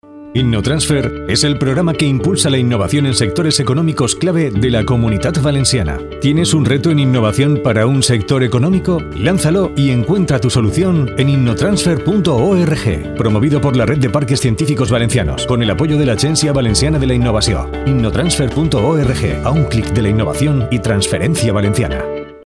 Campaña Radiofónica Innotransfer 2021
Escucha la campaña Innotransfer en Cadena SER realizada del 3 de noviembre al 21 de diciembre de 2021